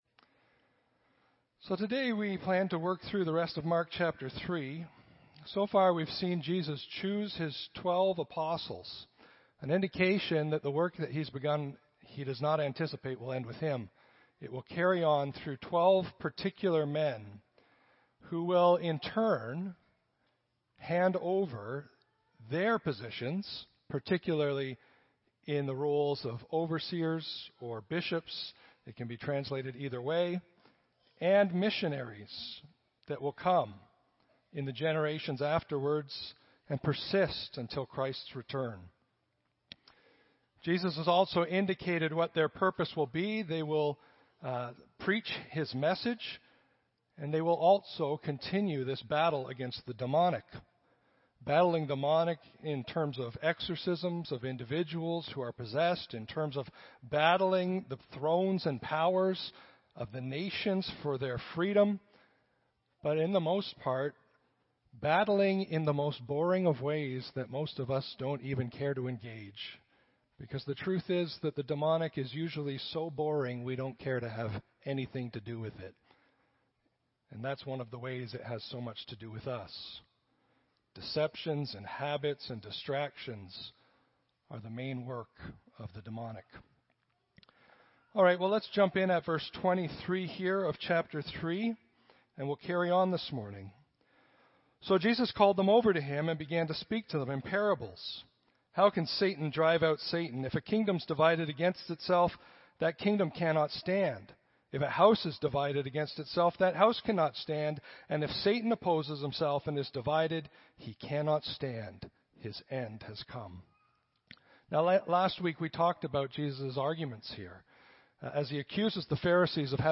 Audio Sermons - Clive Baptist Church